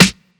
Snare MadFlavor 7.wav